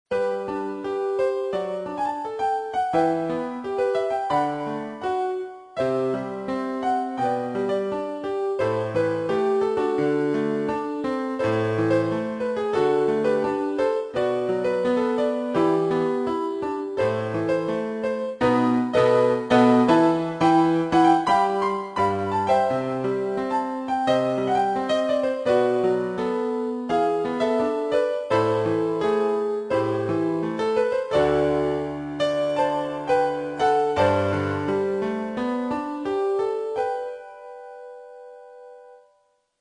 Piano (Format:mp3,56kBit/s,24kHz,Stereo Size:271kB)